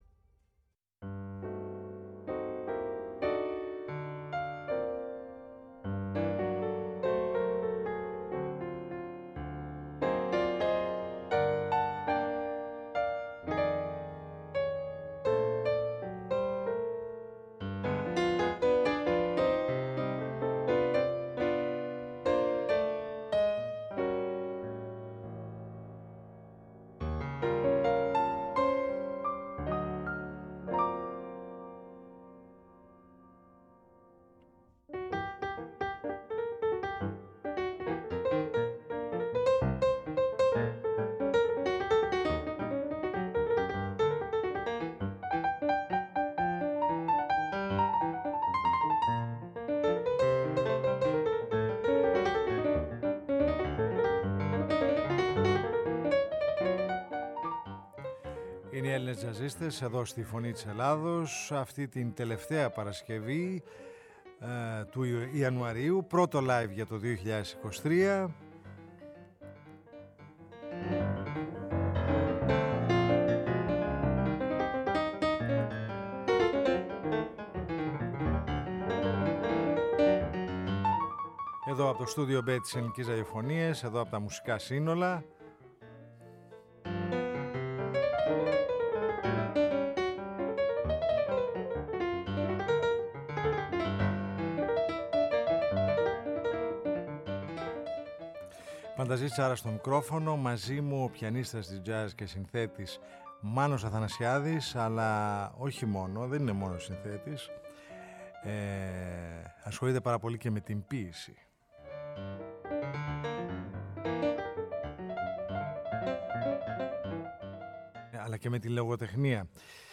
Μια μουσικοποιητική συνάντηση στο στούντιο Β της ΕΡΑ και των μουσικών συνόλων, με τα κείμενα του πιανίστα, επιλογές από τη διεθνή και την Ελληνική βιβλιογραφία καθώς και τραγούδια του τζαζίστα
Πιάνο – Φωνή